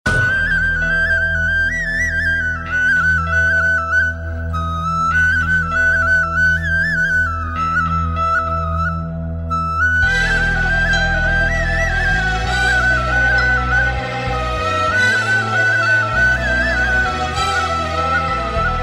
Nice mix Klingeltöne
Electronica